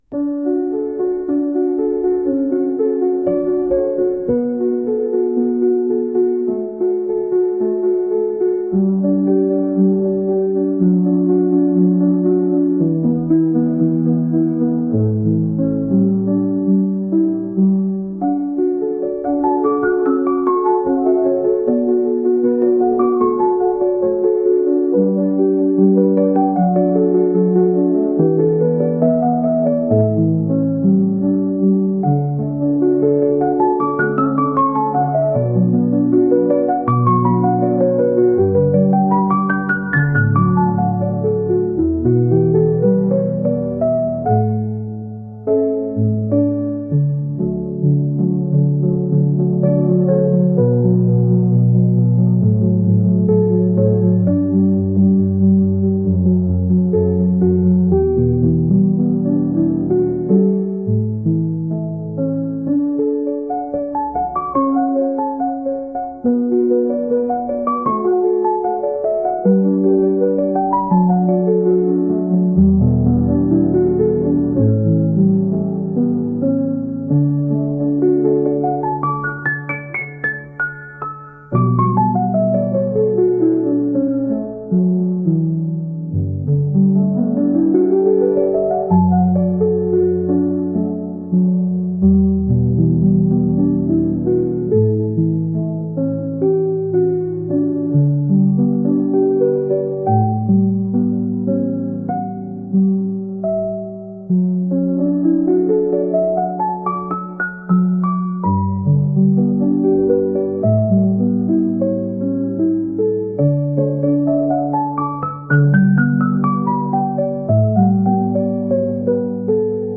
滝の音 Ⅱ
滝の音-1.mp3